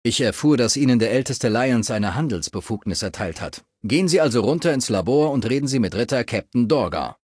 Maleadult01default_dialogueci_citknightdirect_00026c45.ogg (OGG-Mediendatei, Dateigröße: 65 KB.
Fallout 3: Audiodialoge